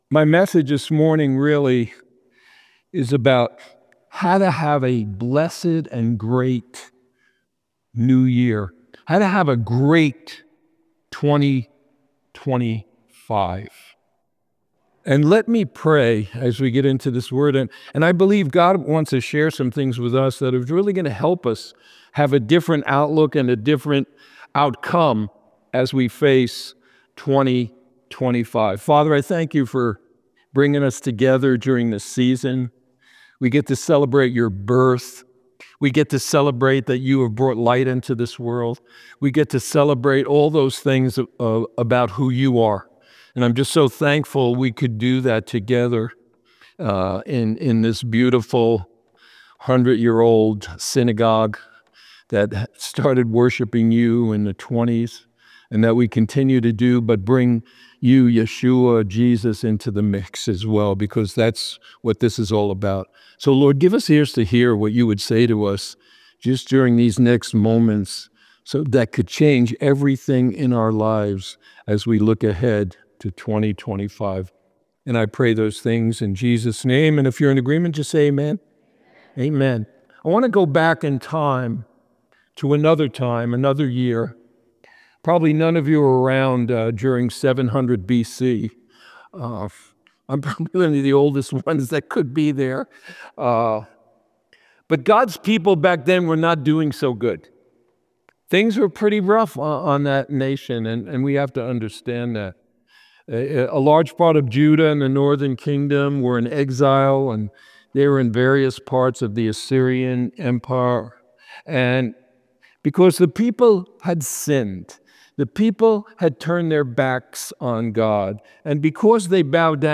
Replay of the weekend services